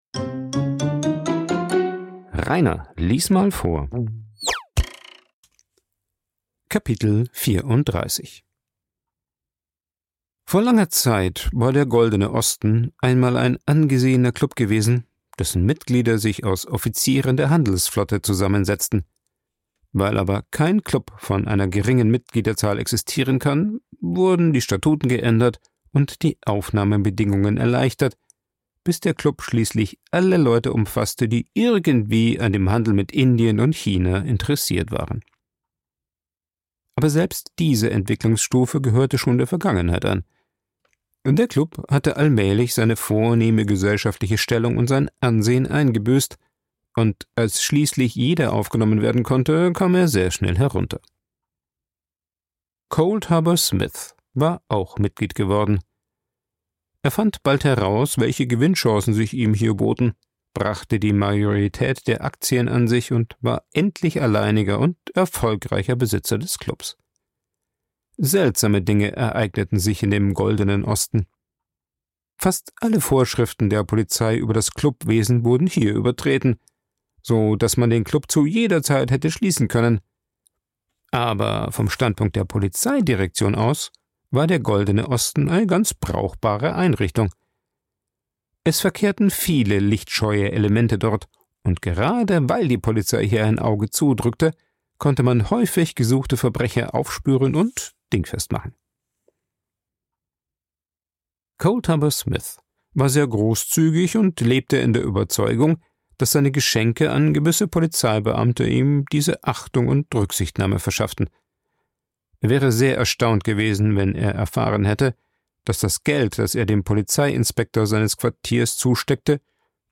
Ich lese Bücher vor, vorwiegend Klassiker der Weltliteratur, weil ich Spass am Vorlesen habe. Jeden 2. oder 3. Tag wird ein Kapitel eines Buches veröffentlicht, so dass mit der Zeit komplette Hörbücher entstehen.